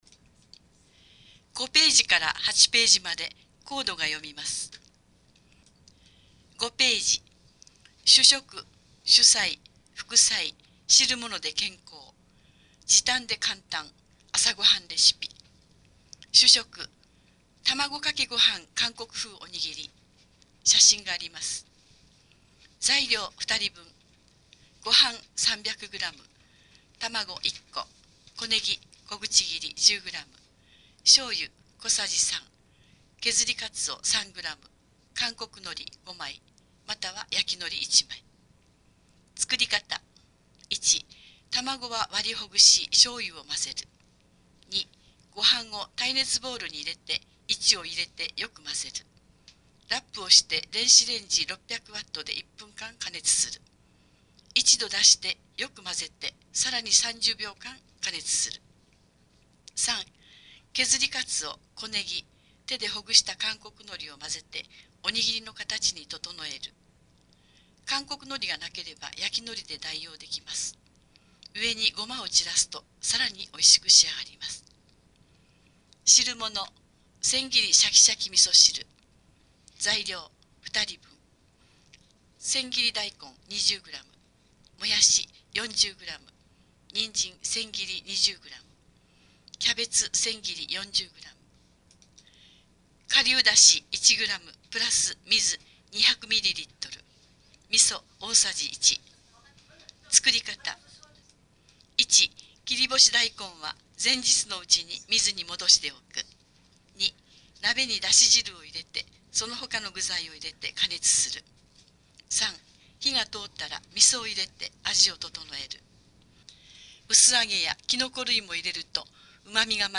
越前市広報６月号（音訳）